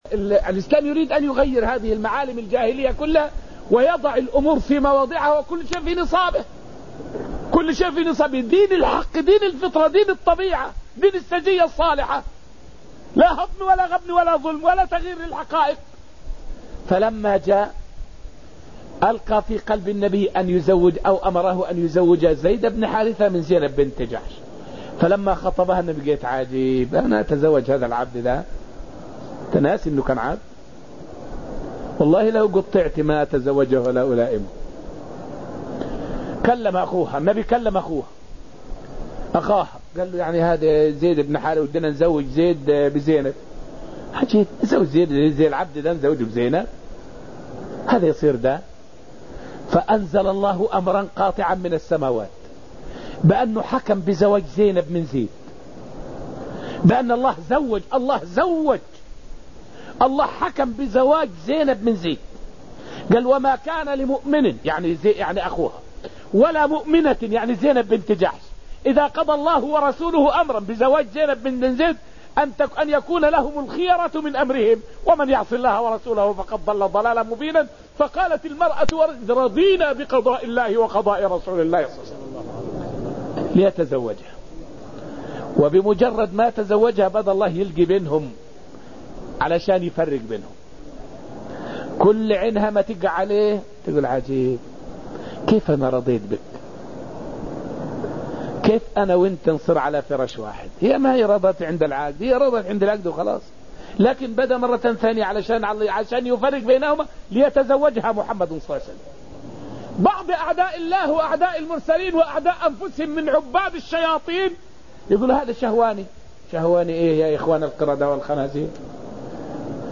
فائدة من الدرس الثاني من دروس تفسير سورة المجادلة والتي ألقيت في المسجد النبوي الشريف حول الرد على شبهة حول زواج النبي من زينب بنت جحش.